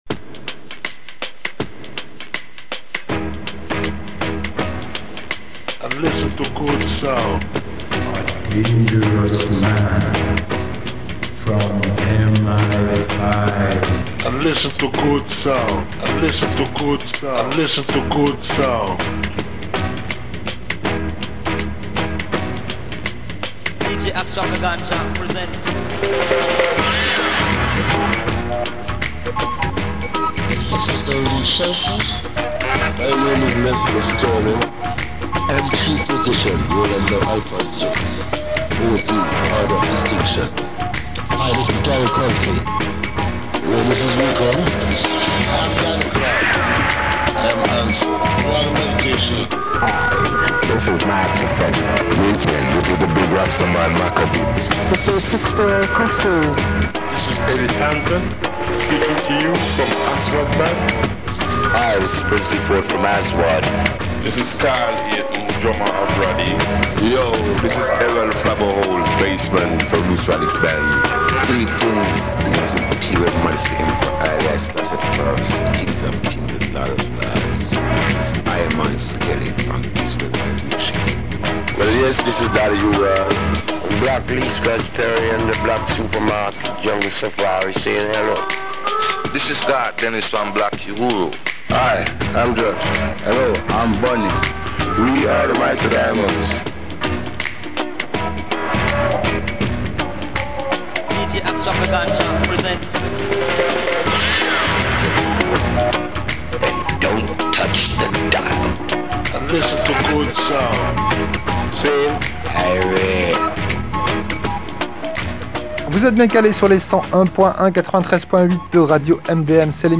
Black Super Market – radio show ! dub, salsa, funk, mestizo, ska, afrobeat, reggaeton, kompa, rumba, reggae, soul, cumbia, ragga, soca, merengue, Brésil, champeta, Balkans, latino rock…
Black Super Market – radio show !